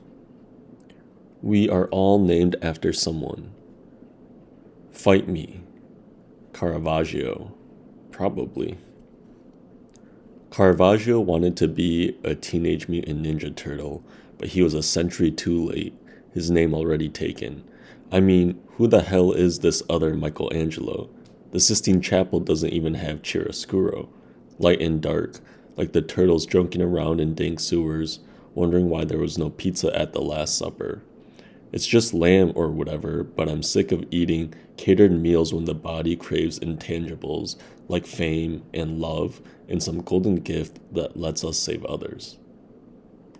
We-Are-All-Named-After-Someone-reading.mp3